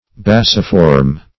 Bacciform \Bac"ci*form\, a.